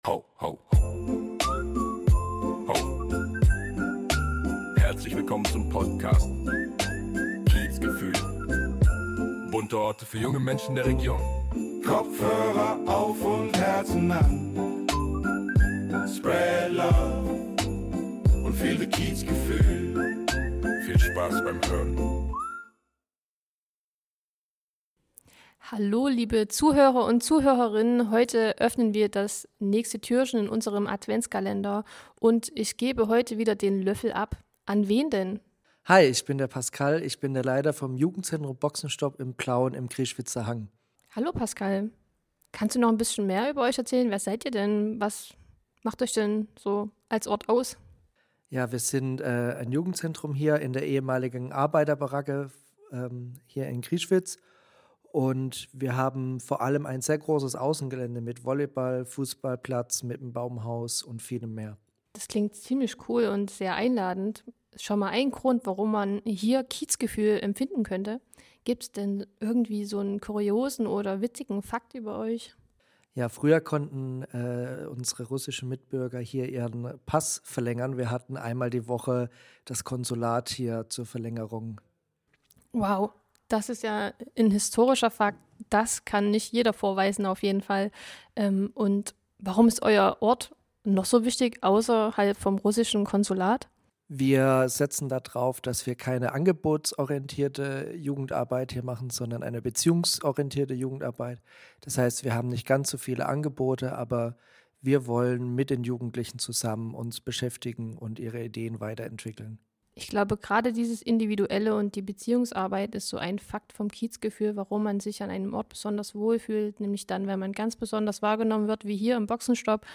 Wir sind im Jugendzentrum Boxenstop, einem Ort, an dem individuell auf eure Bedürfnisse eingegangen wird.
Wir haben einige Versprecher aufgearbeitet und versteckt.